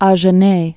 zhə-nā) or Ag·e·nois (-nwä)